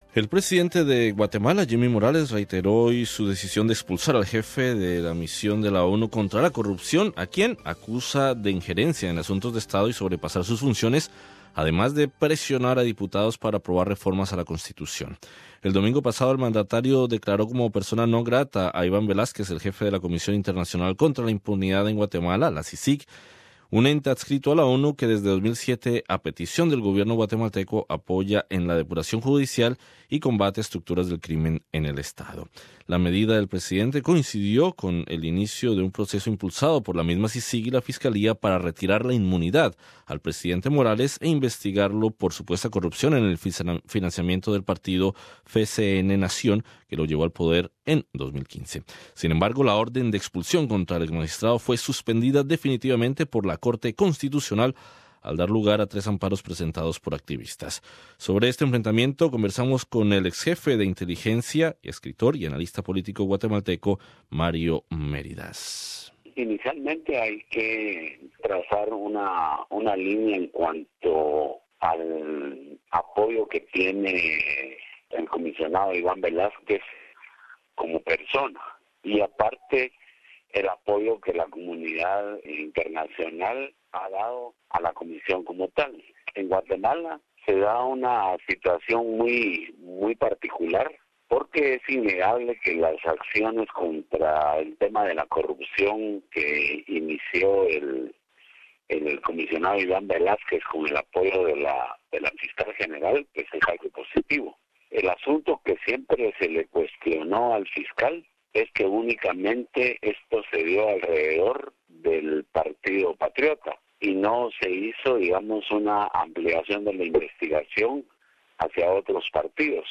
Entrevista con el exjefe de inteligencia